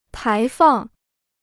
排放 (pái fàng) พจนานุกรมจีนฟรี